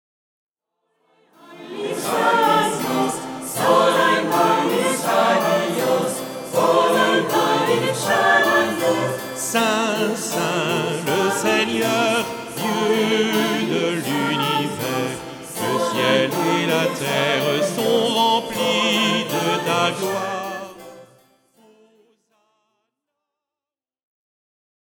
) sont servis par des instruments à cordes et piano.